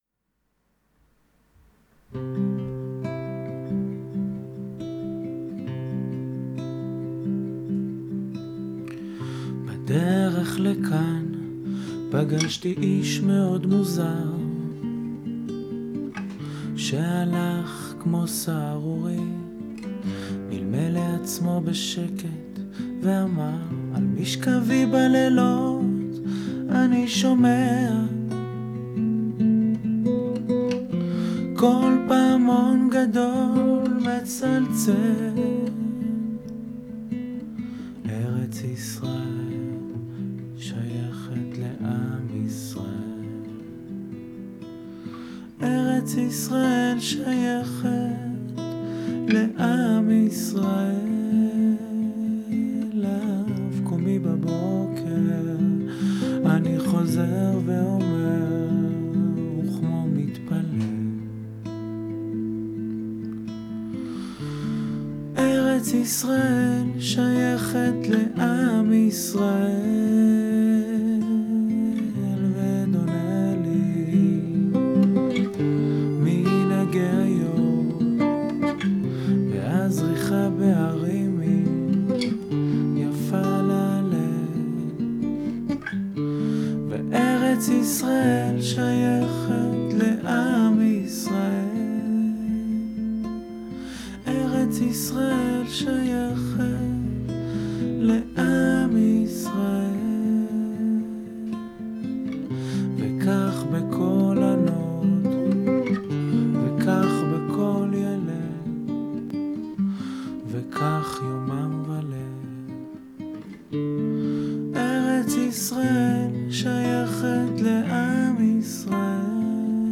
גיטרה קלאסית